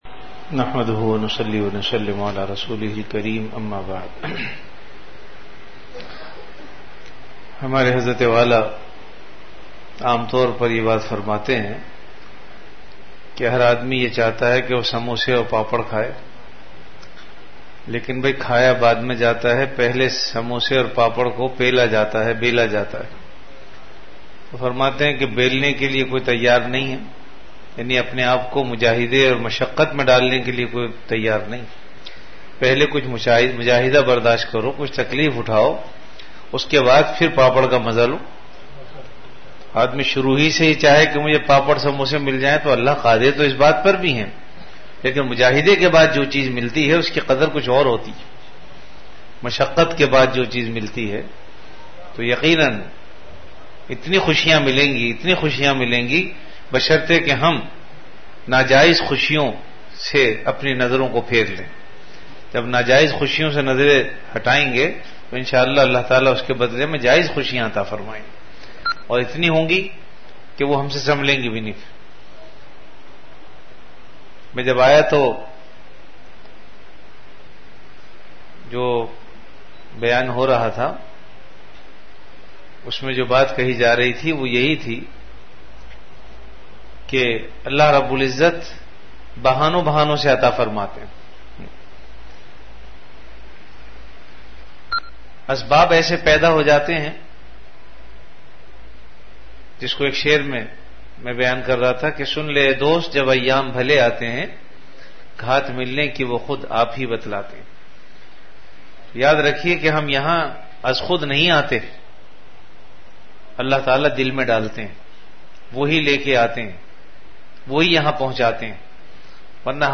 An Islamic audio bayan
Delivered at Home.